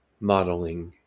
Ääntäminen
Ääntäminen US